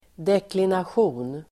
Ladda ner uttalet
Uttal: [deklinasj'o:n]